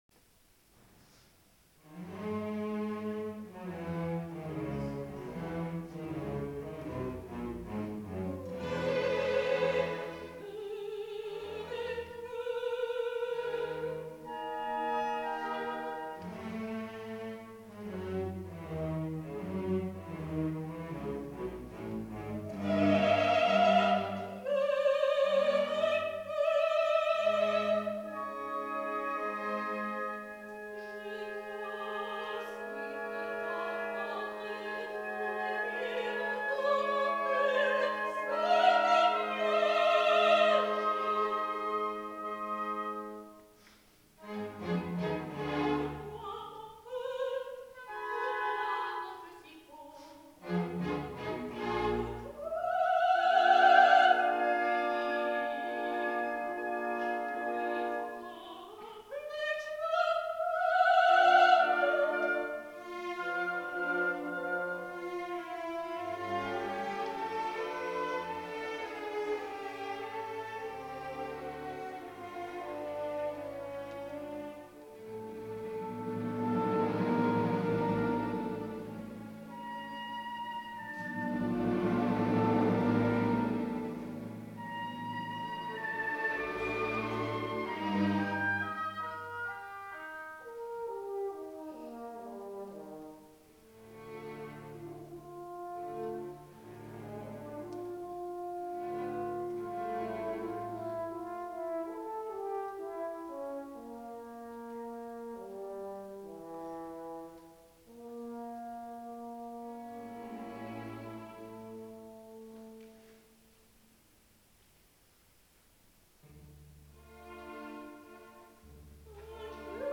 Sopranistin